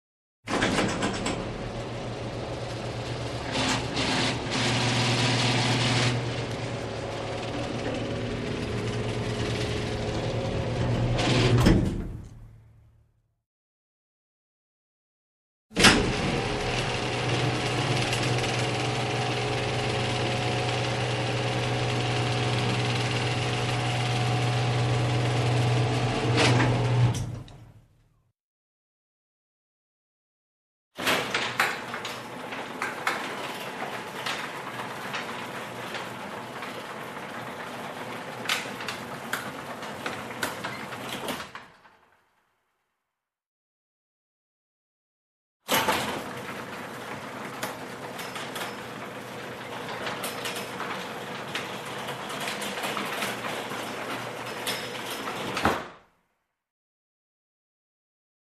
Электрическая дверь гаража закрывается
Тут вы можете прослушать онлайн и скачать бесплатно аудио запись из категории «Двери, окна».